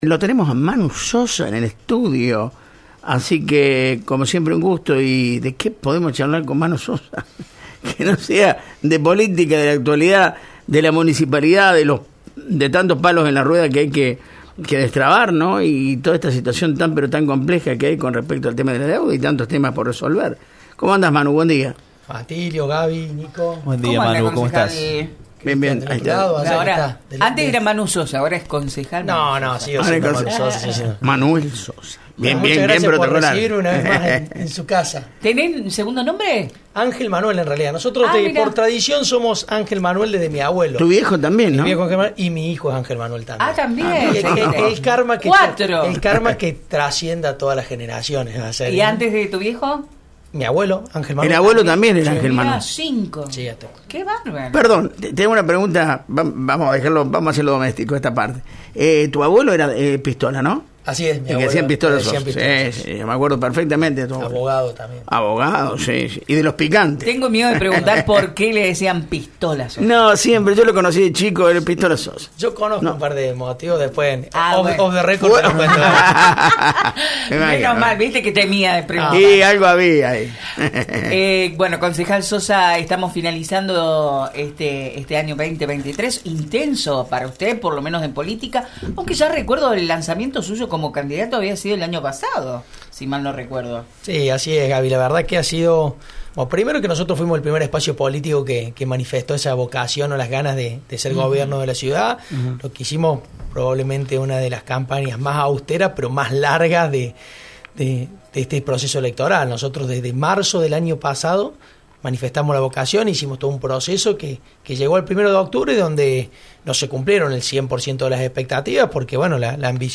El Concejal Manuel Sosa, del bloque unipersonal Uniendo Villa María, visitó el estudio de Radio Centro y en «La Mañana Informal» repasó lo que fue la última sesión del año de la nueva conformación del Concejo Deliberante local. Además, señaló algunas miradas que lo distinguen a su criterio del resto de concejales, ya que contó que algunas votaciones terminaron 11 a 1 en el Concejo.